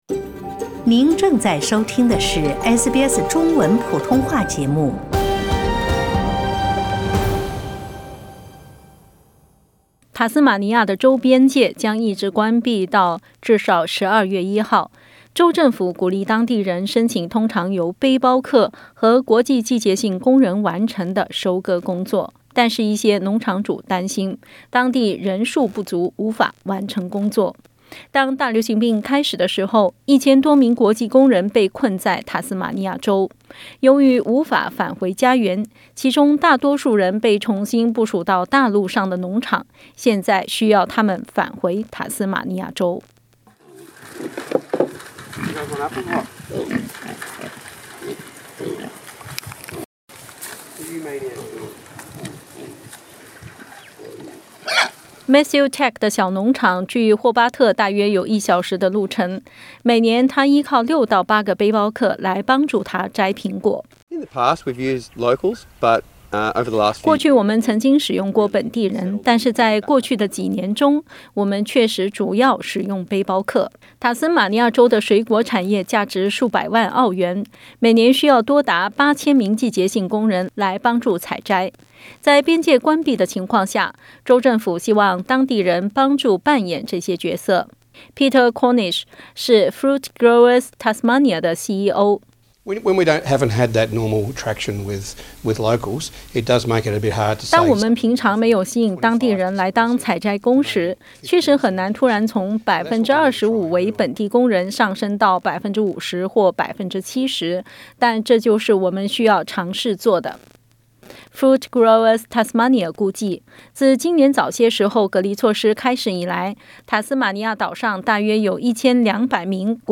塔斯馬尼亞州的邊界將一直關閉到至少12月1日，州政府鼓勵噹地人申請通常由背包客和國際季節性工人完成的收割工作。 但是一些農農場主擔心噹地沒有足夠的季節性工人。 點擊圖片收聽詳細報道。